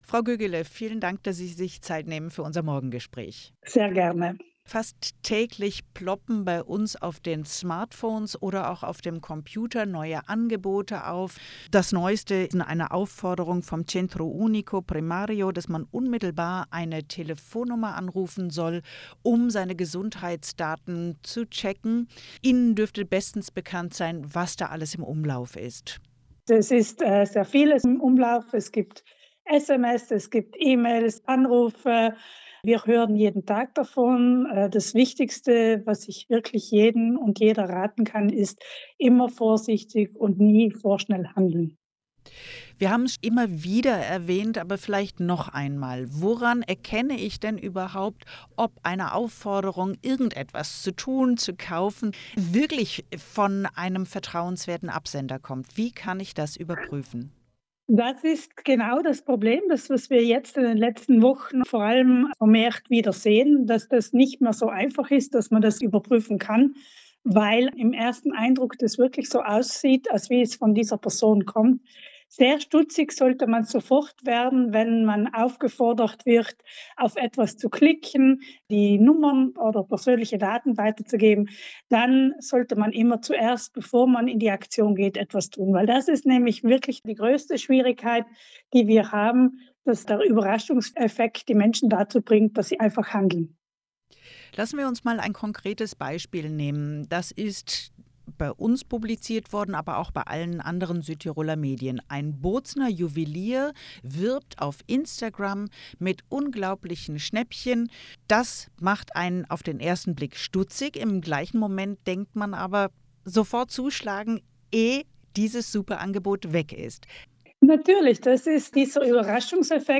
AUDIO: Morgengespräch RAI vom 16.01.2026